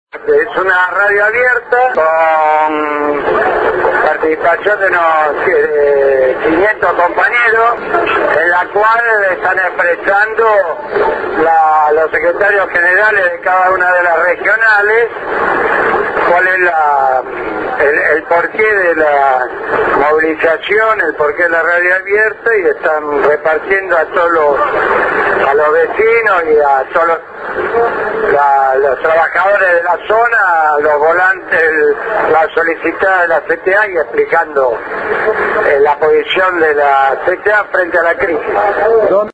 M�s de 500 compa�eros movilizan en la avenida General Paz y Maip� desde las 11 de la ma�ana.